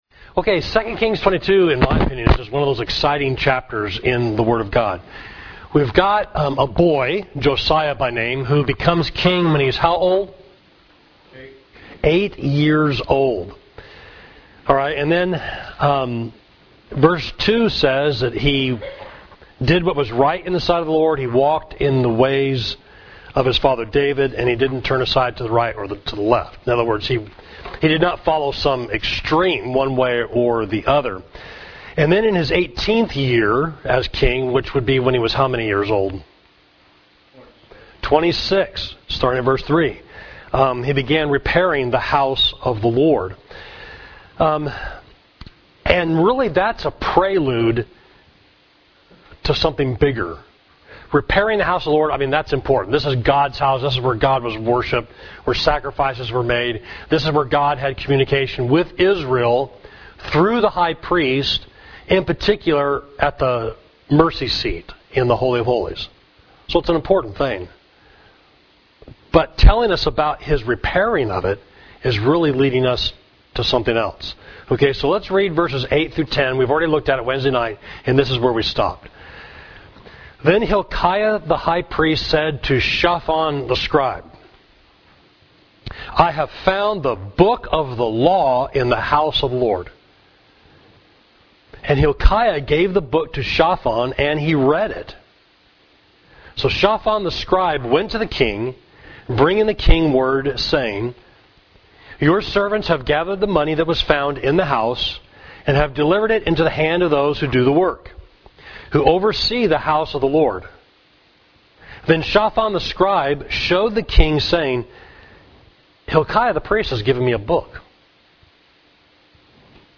Class: Someone Found a Book, Second Kings 22